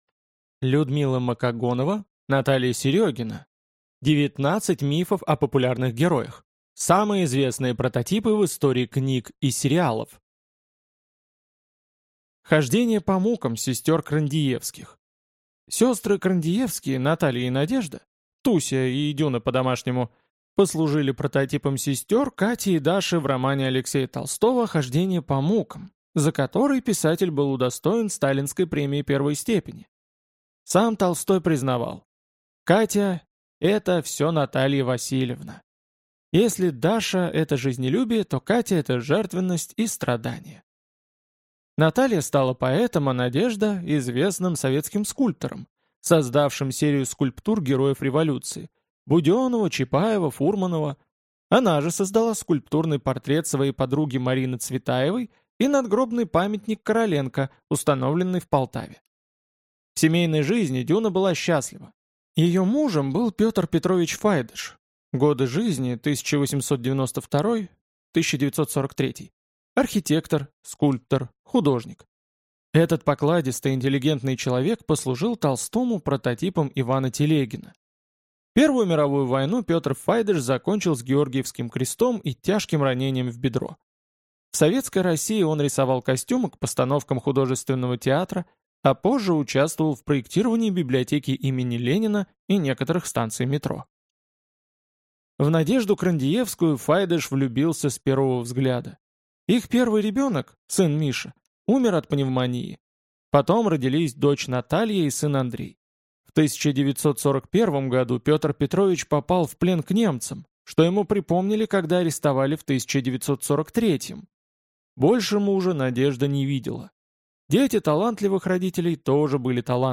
Аудиокнига 19 мифов о популярных героях. Самые известные прототипы в истории книг и сериалов | Библиотека аудиокниг